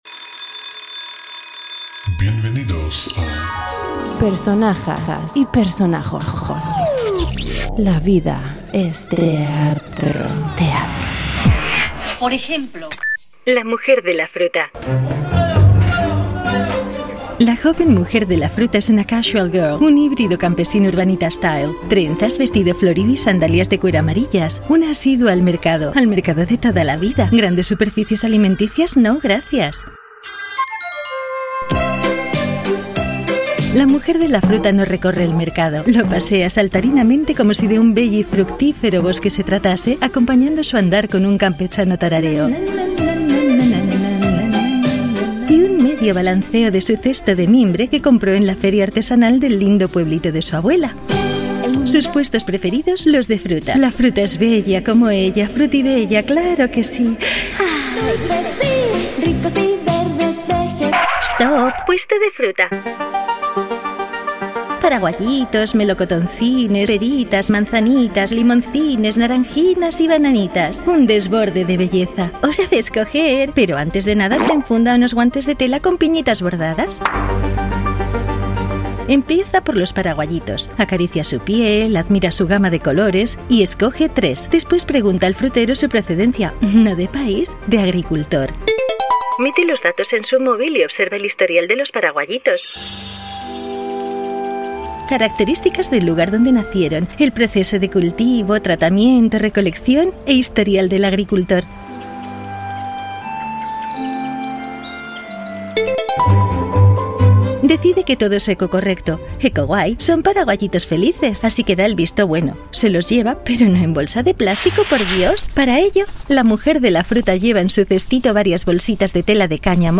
Ficción sonora en Boulevard Magazine.